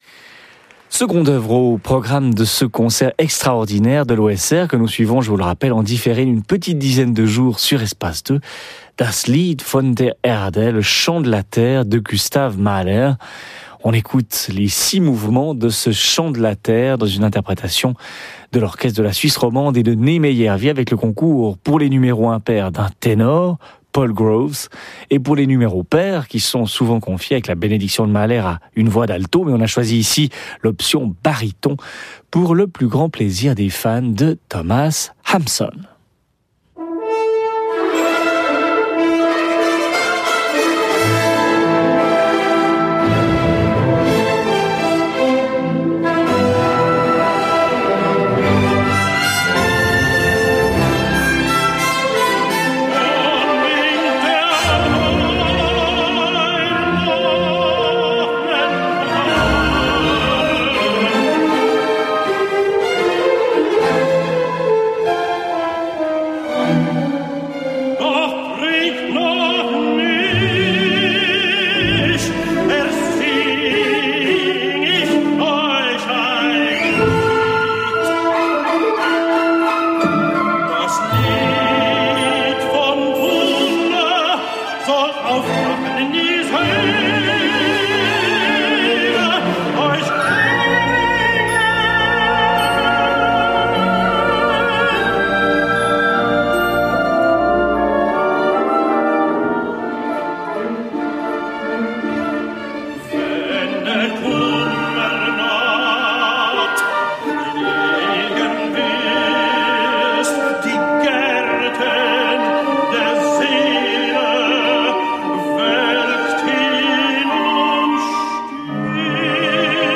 Joining the Orchestra as soloists are Thomas Hampson, Baritone and Paul Groves, Tenor for Mahler’s Das Lied von der Erde.
It was recorded live, in concert by RSR Espace 2 this past September 13th.
Here’s what’s on the players: Transmission différée du concert donné le jeudi 13 septembre au Victoria Hall à Genève.
– G. Mahler , Wang-Wei: Le chant de la terre. Symphonie pour baryton, ténor et orchestre One of those perfect concerts to put your feet up and get comfortable over.